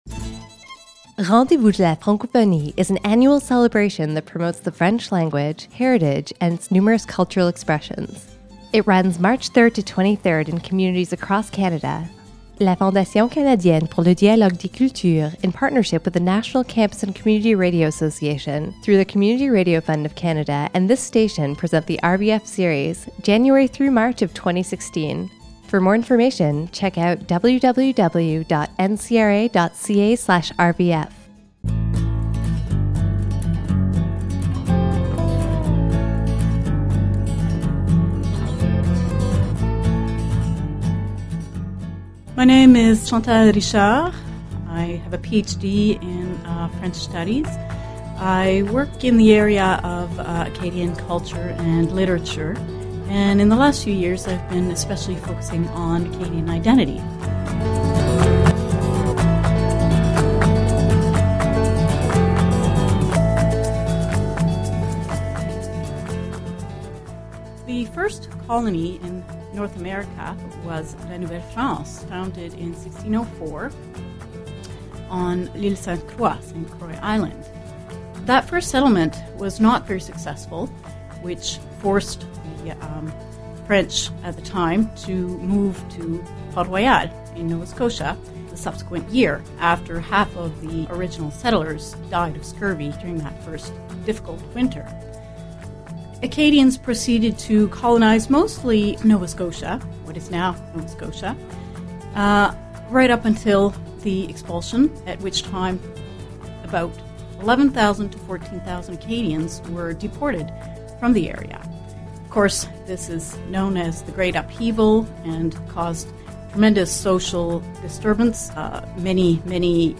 Recording Location: Fredrickton, NB
Type: Weekly Program
320kbps Stereo